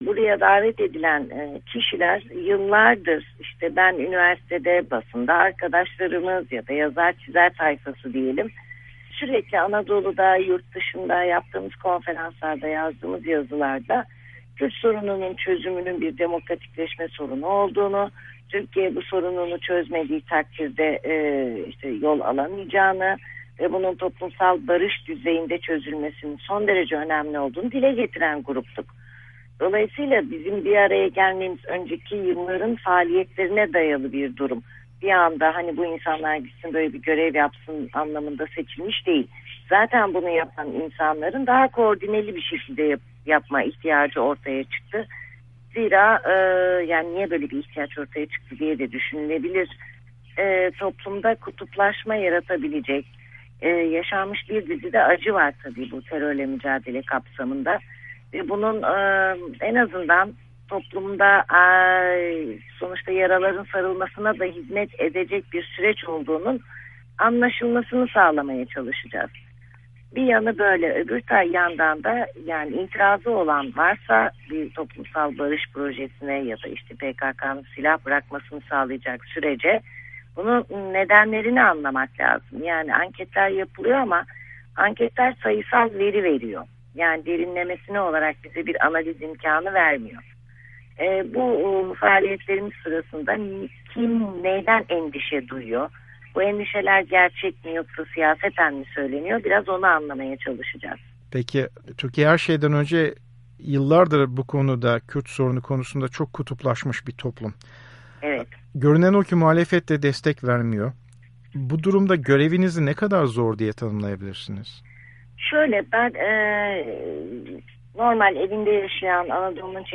Beril Dedeoğlu'yla söyleşi